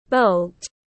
Mũi tên nỏ tiếng anh gọi là bolt, phiên âm tiếng anh đọc là /bəʊlt/